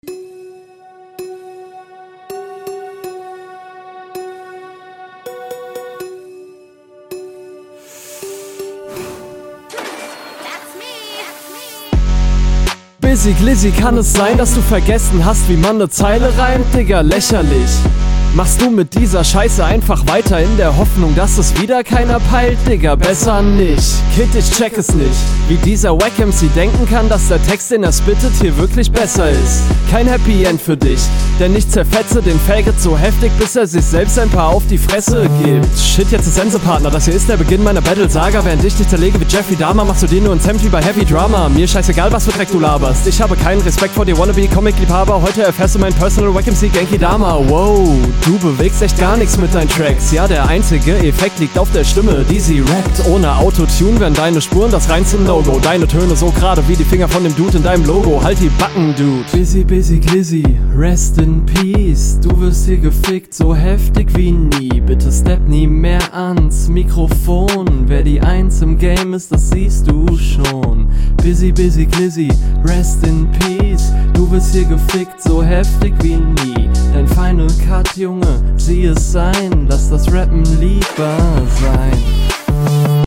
Kommst auf deinem Beat zum Glück besser als in RR1.